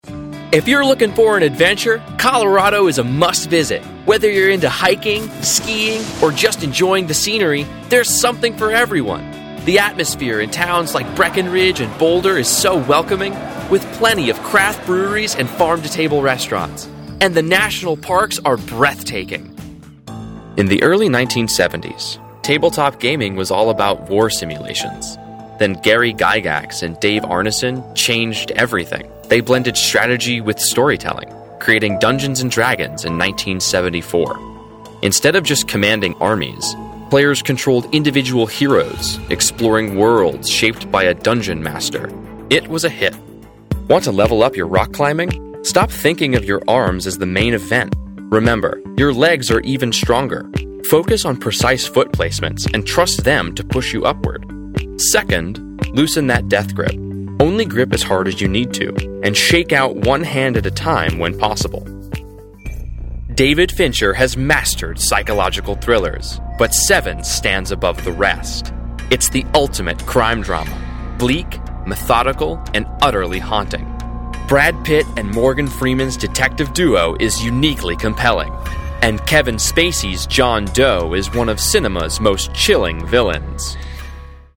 Authentic, Conversational, Executive, and Robust.
Narration Demo
Male, 18-35.